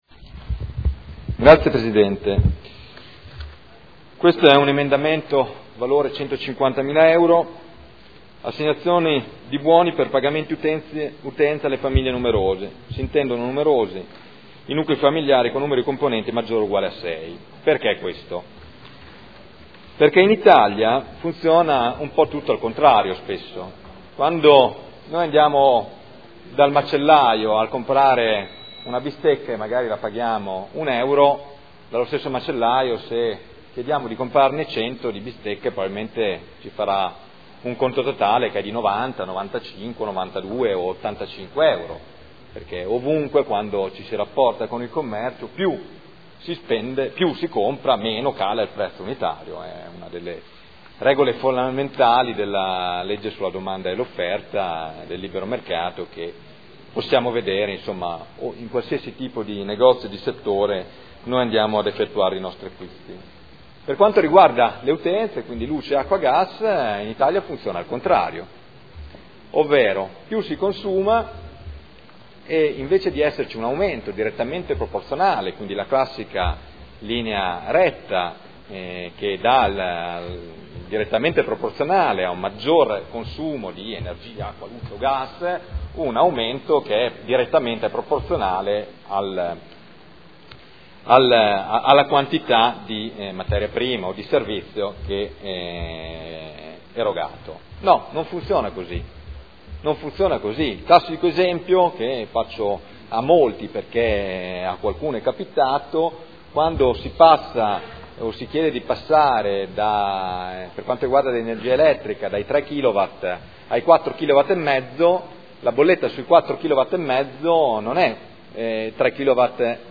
Michele Barcaiuolo — Sito Audio Consiglio Comunale
Seduta del 1/07/2013.